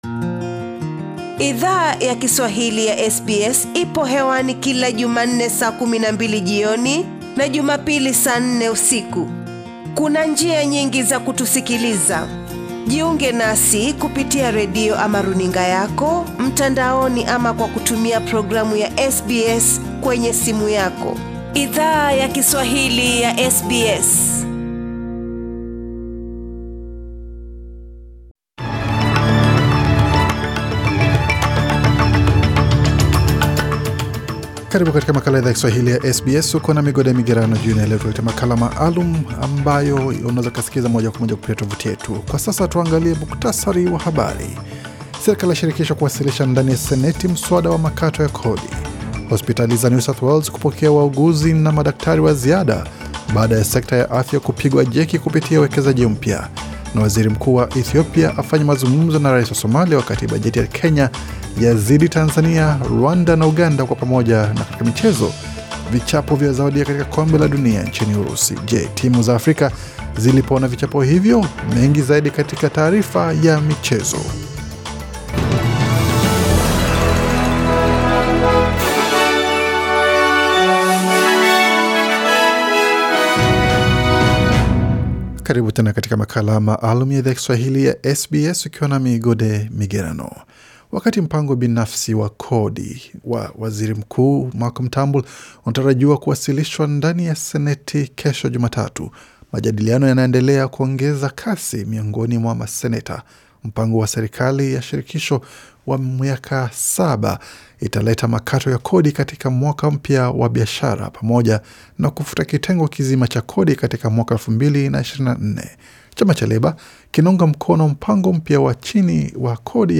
Taarifa ya habari ya Jumapili 17Juni18